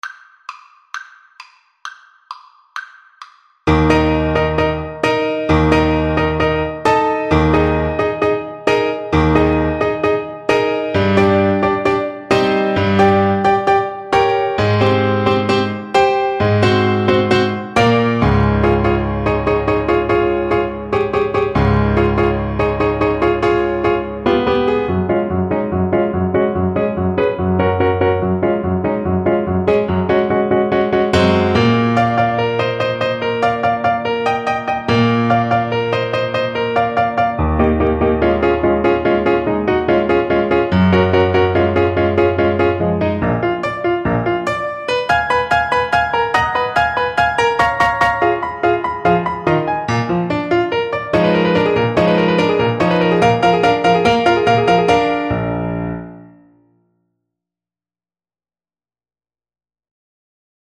Clarinet version
2/4 (View more 2/4 Music)
Allegro =132 (View more music marked Allegro)
Classical (View more Classical Clarinet Music)